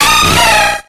AZUMARILL.ogg